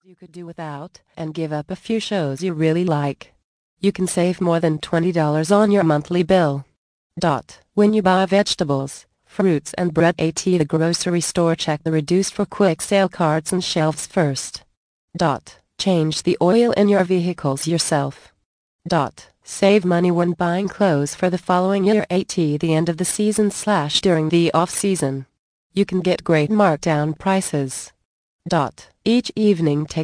Emergency Quick Cash mp3 audio book part 2 of 4 + FREE GIFT